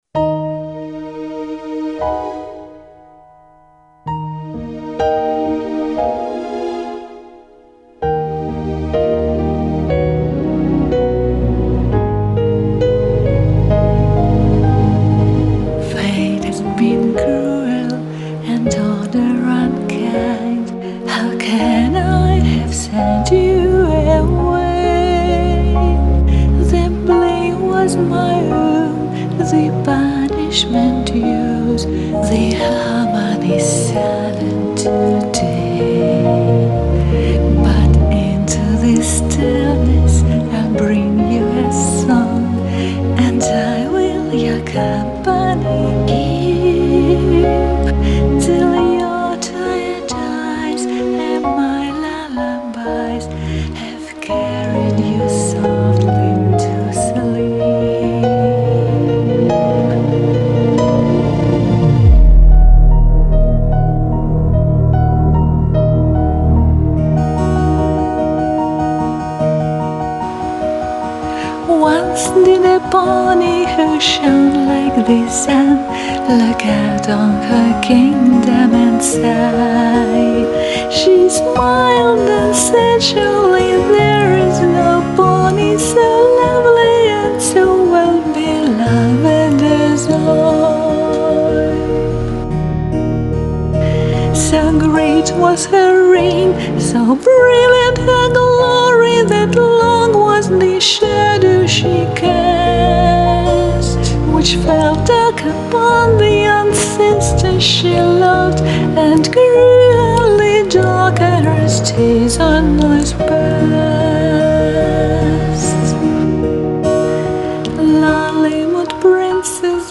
И действительно, пела я приторно.